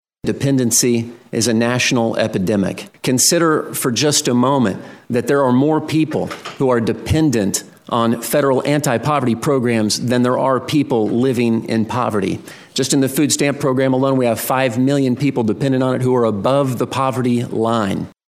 Partisan differences over limiting Supplemental Nutrition Assistance Program (SNAP) spending, which makes up more than 80% of the cost attributed to the federal farm bill, were on full display during a Senate Ag subcommittee hearing.